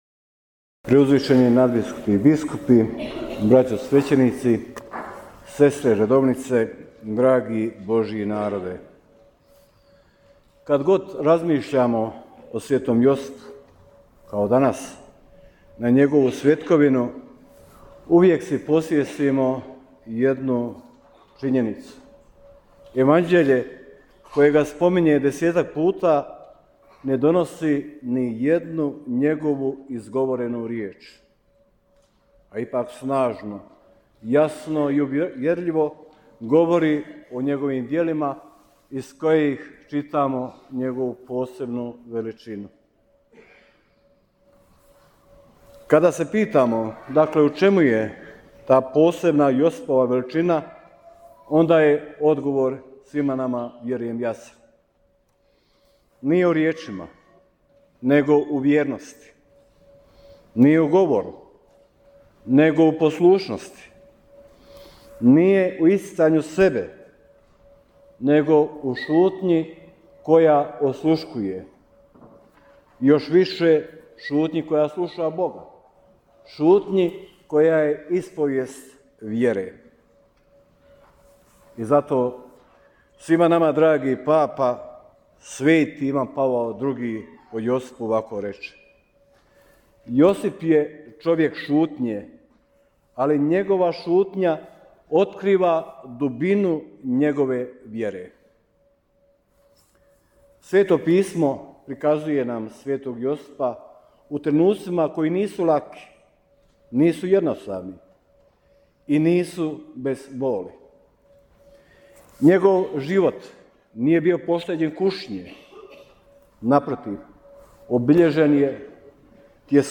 Propovijed biskupa Majića na svetkovinu sv. Josipa u mostarskoj katedrali - BANJOLUČKA BISKUPIJA
Svečanim euharistijskim slavljem koje je 19. ožujka 2026. u mostarskoj katedrali Marije Majke Crkve predvodio biskup banjolučki mons. Željko Majić, Mostarsko-duvanjska biskupija proslavila je svoga nebeskog zaštitnika sv. Josipa.